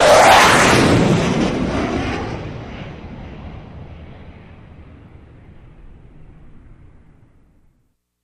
F-16 Fighting Falcon
F-16 Fast By